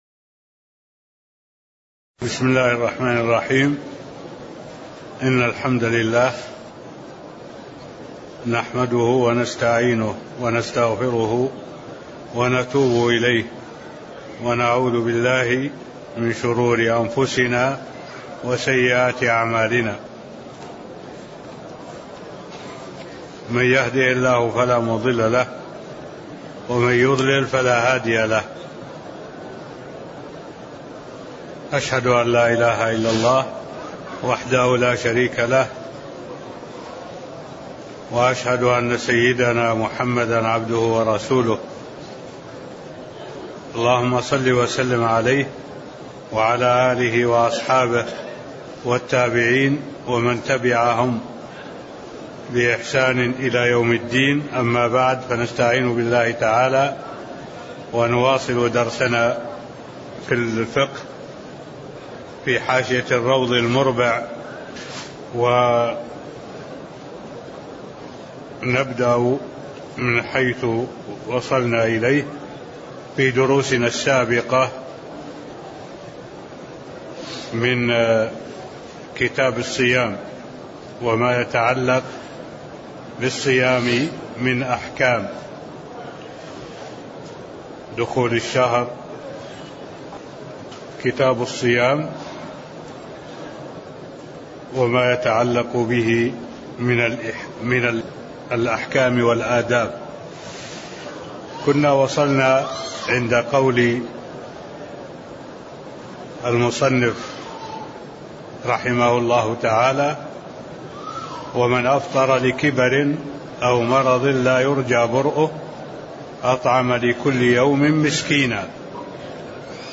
المكان: المسجد النبوي الشيخ: معالي الشيخ الدكتور صالح بن عبد الله العبود معالي الشيخ الدكتور صالح بن عبد الله العبود كتاب الصيام من قوله: (فمن أفطر لكبر أو مرض لا يرجى برؤه) (05)